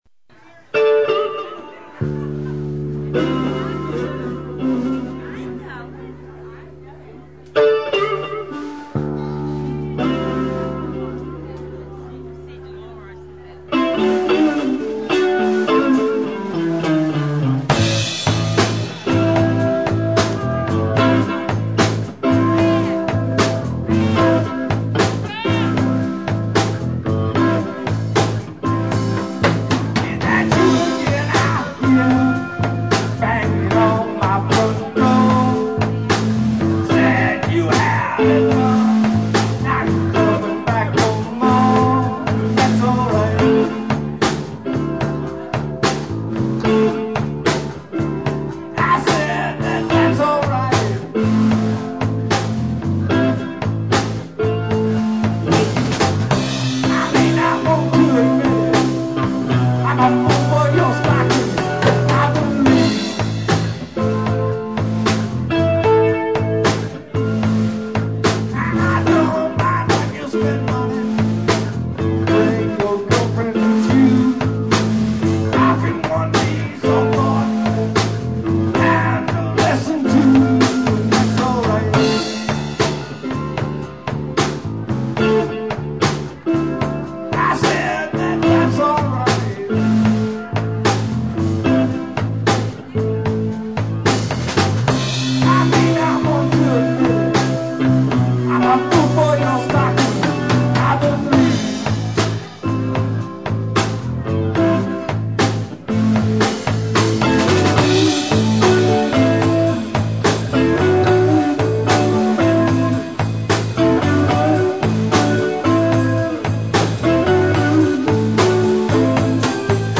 ROCK, BLUES, JAZZ AND MORE